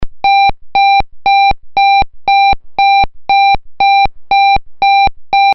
Sirena electrónica